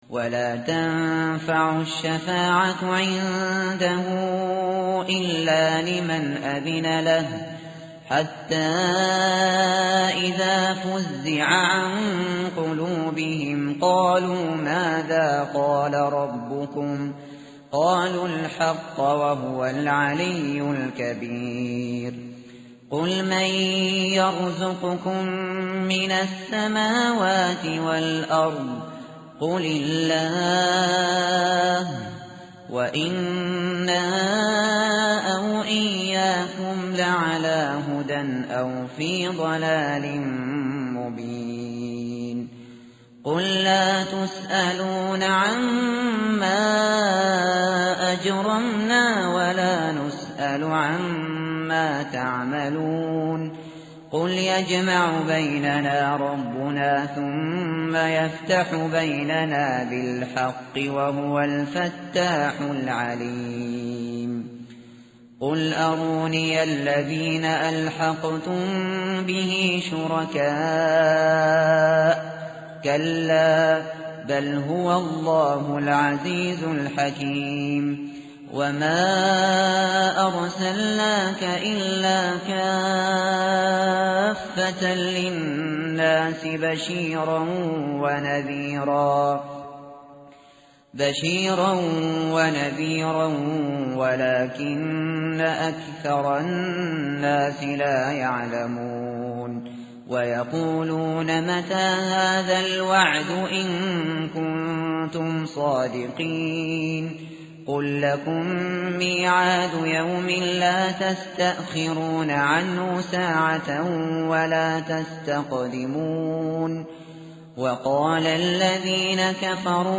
tartil_shateri_page_431.mp3